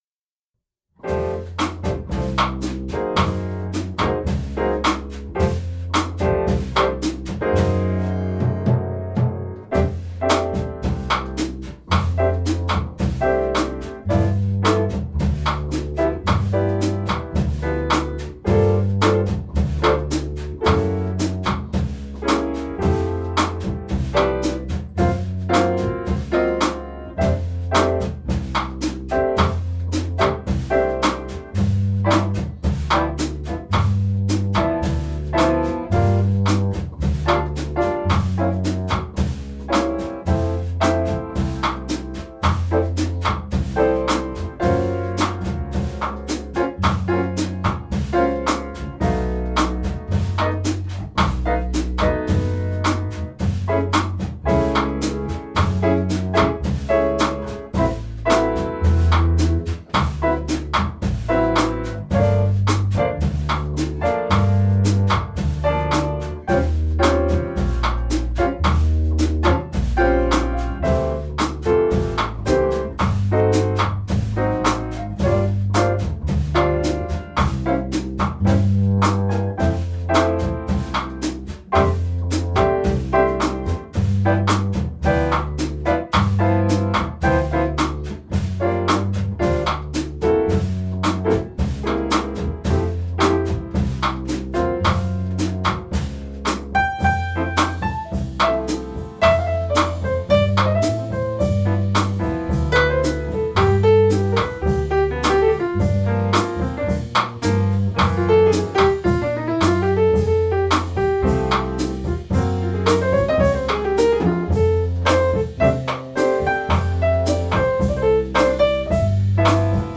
Exemples de play-backs (versions de démonstration) :
Tempo 110 –  Intro, thème, chorus piano, thème